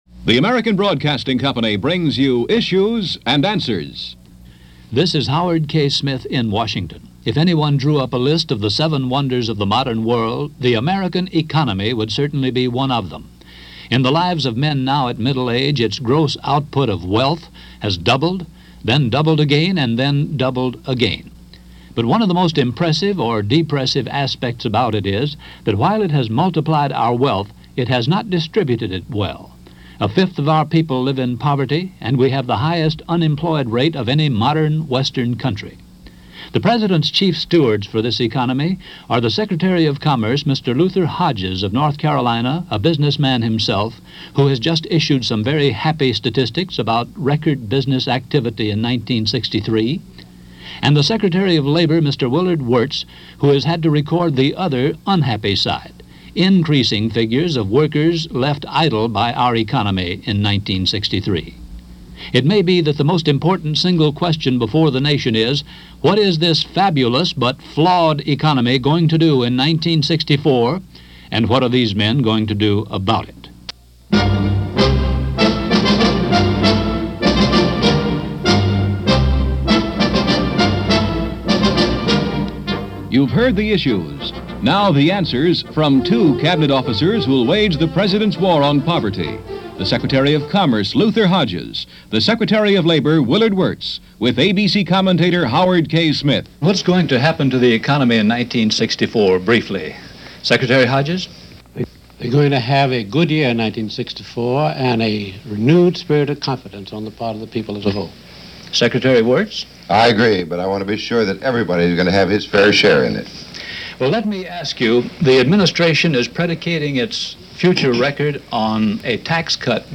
The question on most people’s minds in 1964 was; what was this fabulous, but flawed economy going to do in the coming year? This episode of ABC’s Issues and Answers features Commerce Secretary Luther Hodges and Labor Secretary Willard Wirtz discussing the then-current situation facing the Johnson White House.